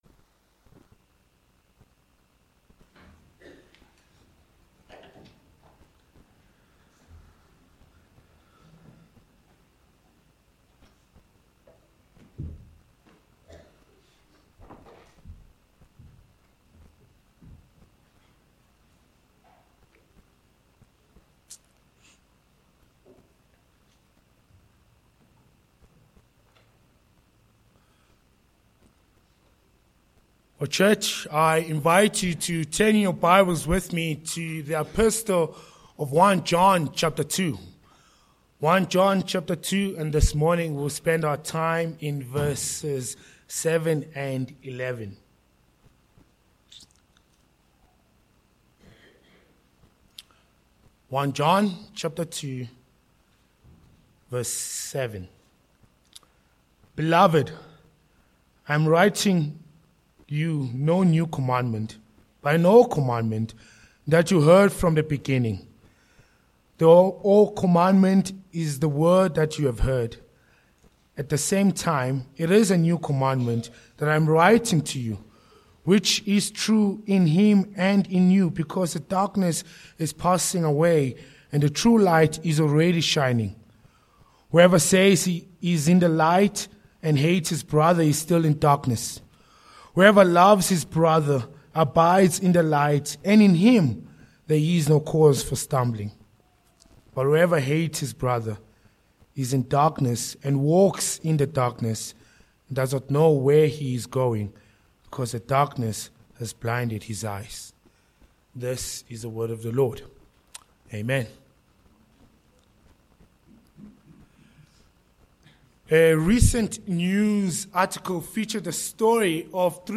1 John 2:7-11 Service Type: Morning Passage